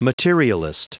Prononciation du mot materialist en anglais (fichier audio)
Prononciation du mot : materialist